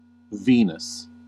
Ääntäminen
Synonyymit Hesperus Ääntäminen US UK : IPA : /ˈviː.nəs/ US : IPA : /ˈvi.nəs/ IPA : [ˈvinɪ̈s] Haettu sana löytyi näillä lähdekielillä: englanti Käännös Konteksti Erisnimet 1. Venus astronomia, mytologia Substantiivit 2. kointähti Määritelmät Erisnimet (Roman god) The goddess of love , beauty , and natural productivity ; the Roman counterpart of Aphrodite .